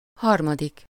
Ääntäminen
Synonyymit troisième inconnu Ääntäminen France: IPA: [œ̃ tjɛʁ] Tuntematon aksentti: IPA: /tjɛʁ/ Haettu sana löytyi näillä lähdekielillä: ranska Käännös Ääninäyte 1. harmad 2. harmadik Suku: m .